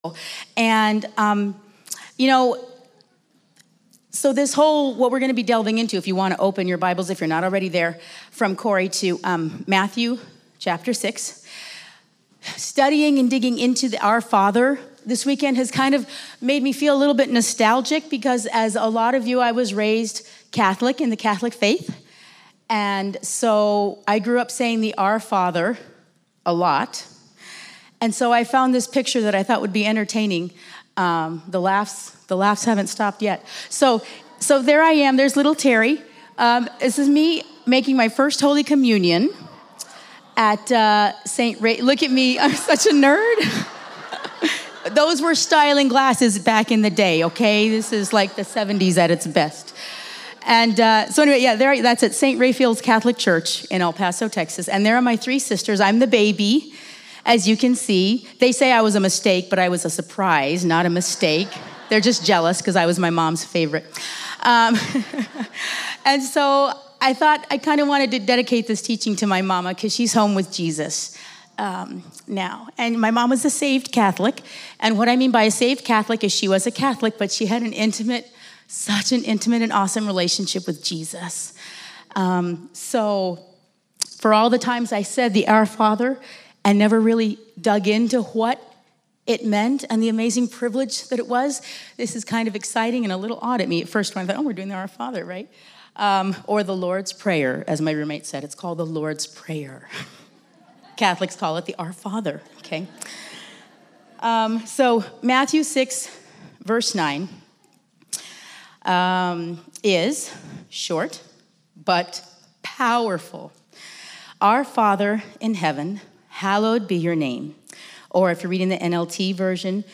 Women's Retreat 2018